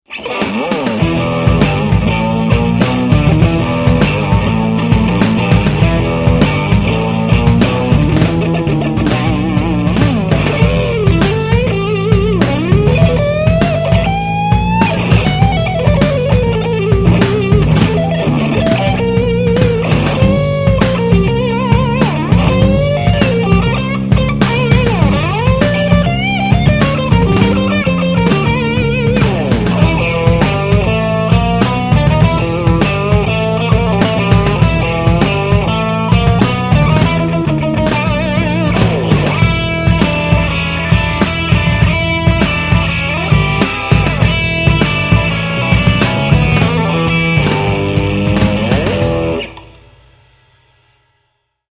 Finally, I also did some demo mini tunes featuring his custom built FX pedals! Enjoy!
The DOD 250 for a nice thick overdriven Strat tone.
DOD250Demo.mp3